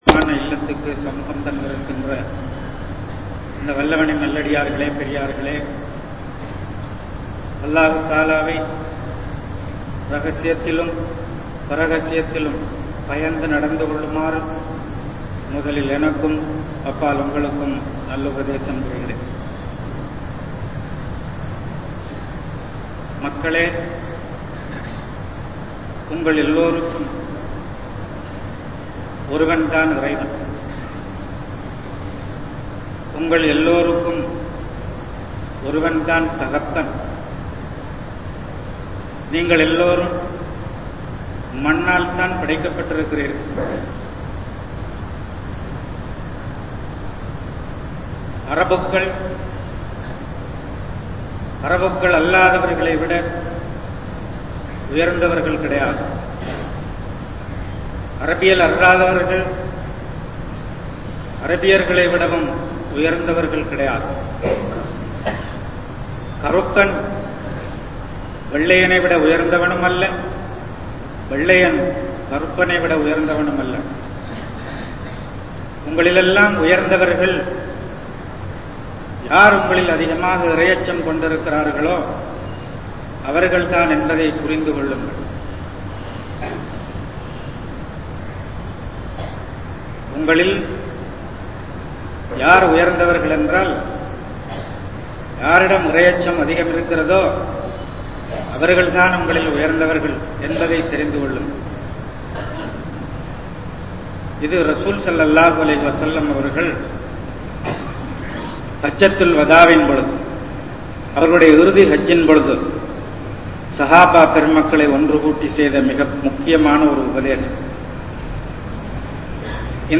Islam Koorum Samaththuvam (இஸ்லாம் கூறும் சமத்துவம்) | Audio Bayans | All Ceylon Muslim Youth Community | Addalaichenai